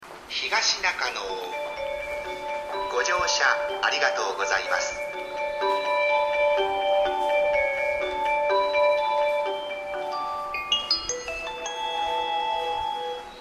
音質は高音質で綺麗に収録が出来ますが音量が小さめです。
２番線JB：中央・総武線(各駅停車)
発車メロディーフルコーラス鳴りましたが最後が接近放送と被られています。